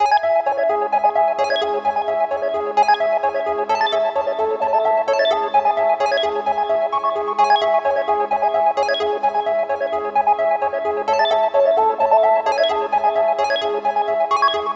陷阱或Hip Hop低音130 BPM
描述：陷阱或Hip Hop低音130 BPM
标签： 130 bpm Trap Loops Bass Synth Loops 1.24 MB wav Key : Unknown FL Studio
声道立体声